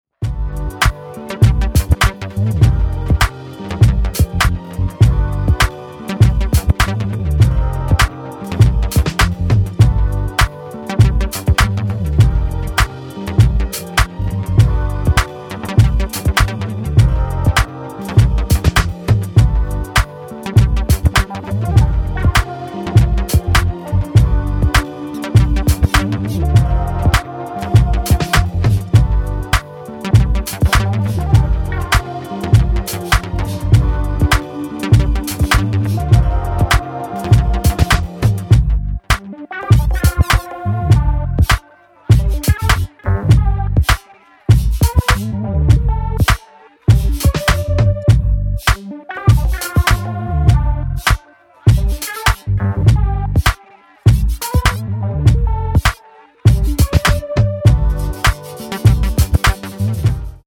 a warm and synthesized soul tune